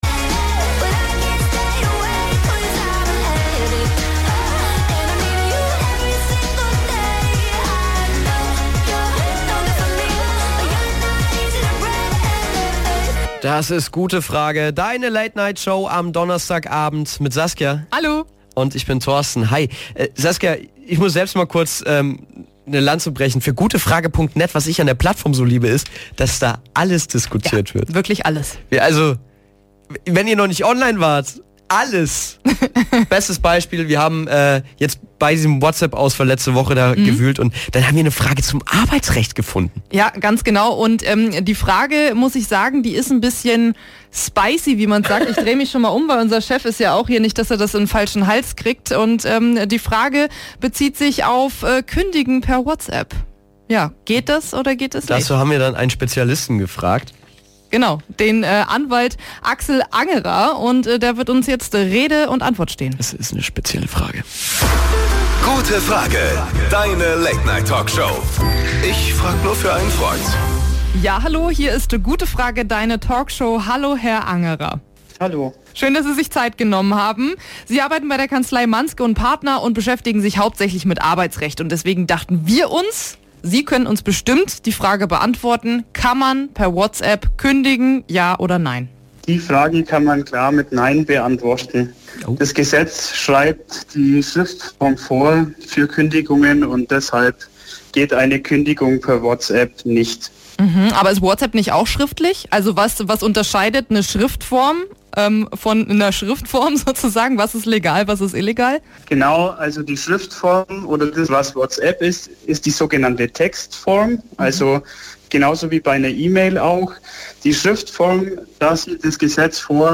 Interview mit Hit Radio N1.